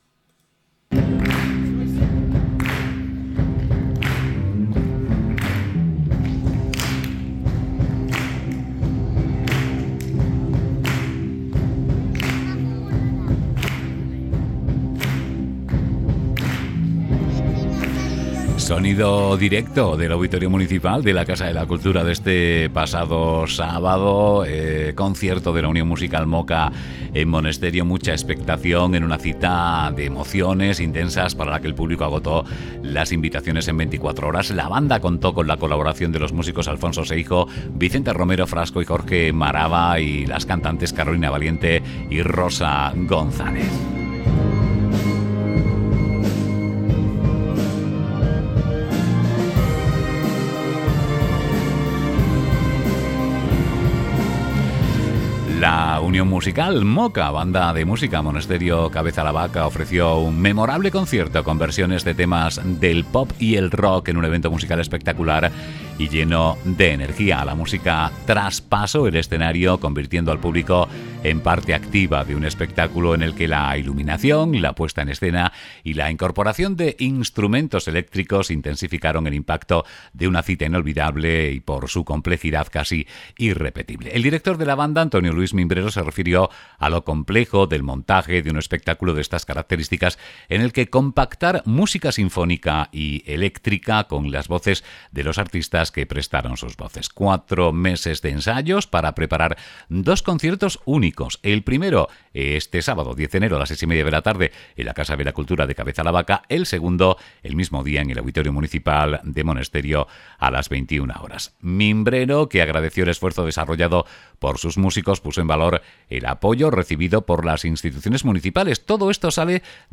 Memorable y espectacular concierto de la Unión Musical MOCA en Monesterio
RjBCWCONCIERTOBANDA.mp3